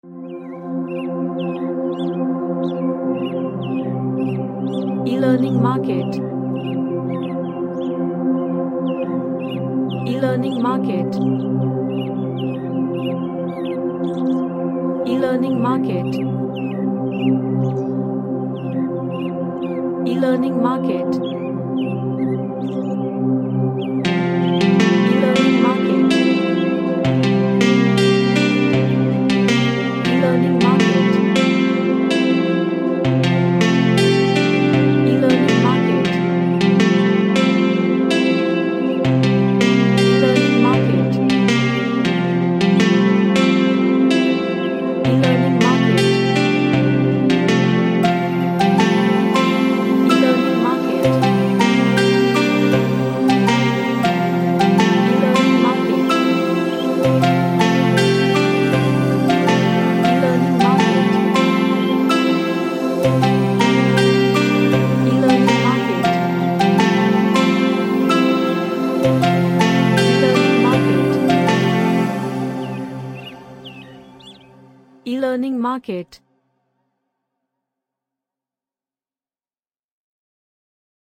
A cinematic guitar track
Gentle / LightSoft